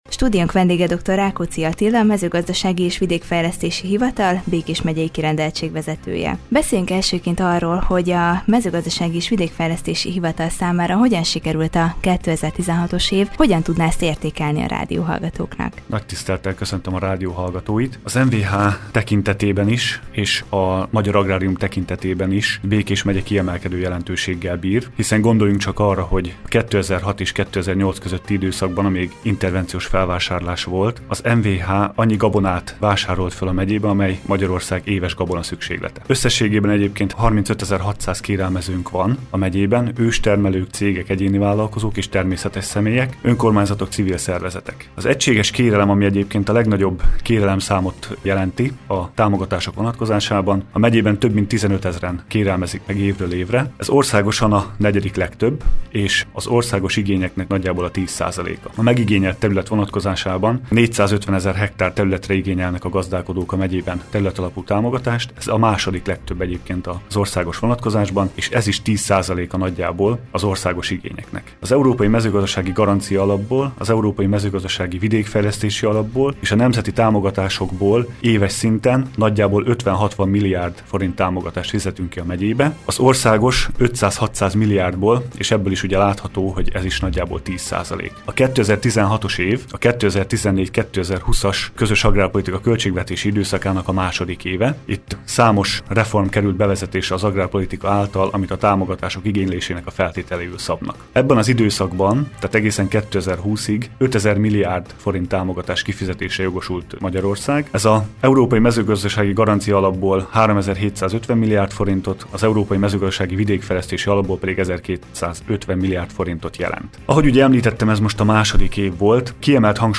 Post navigation Előző hír Sok változás történt a vadászok munkájában 2016-ban Következő hír Nemzetközi sikereket is elért már a Dirty Slippers KATEGÓRIA: Interjúk